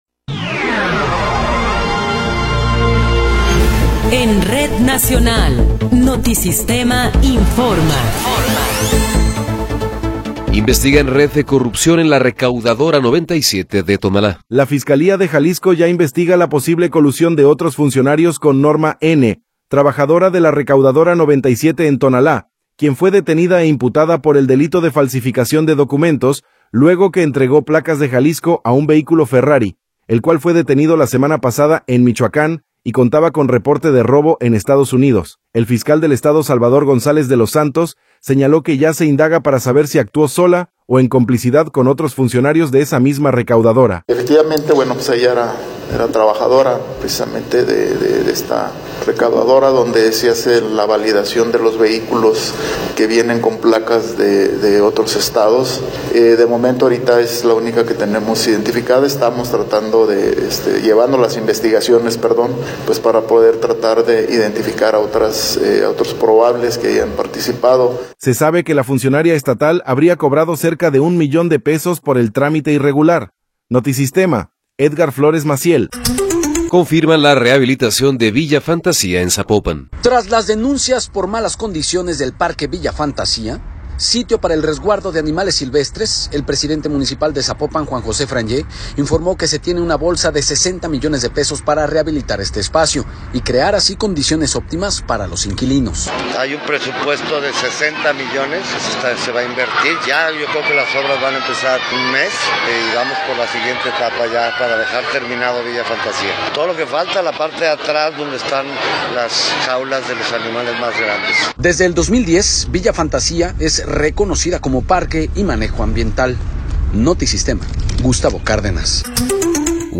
Noticiero 12 hrs. – 29 de Enero de 2026
Resumen informativo Notisistema, la mejor y más completa información cada hora en la hora.